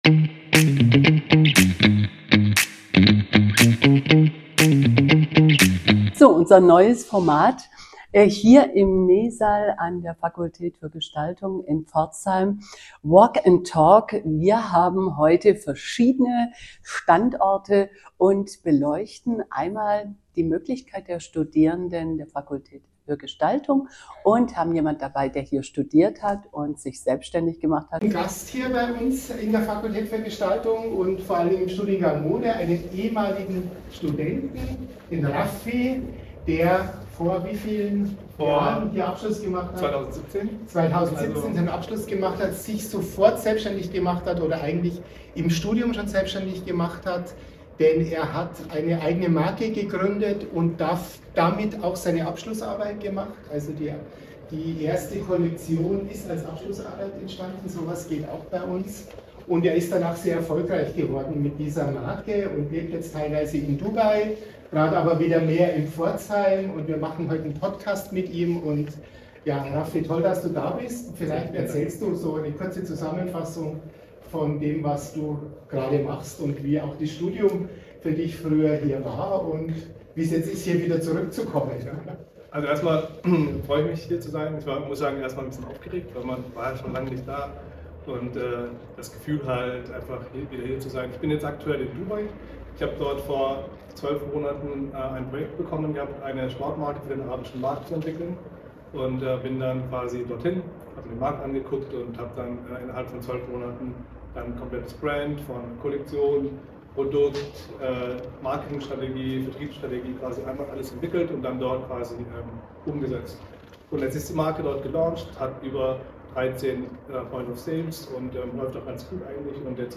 In der ersten Folge der zweiten Staffel nehmen wir euch mit auf ein WALK + TALK an der Hochschule Pforzheim, Fakultät für Gestaltung.